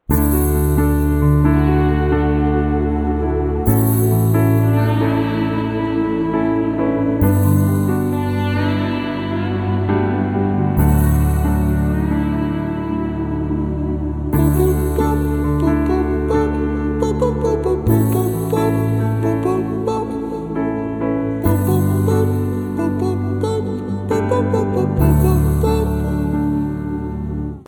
best flute ringtone download | love song ringtone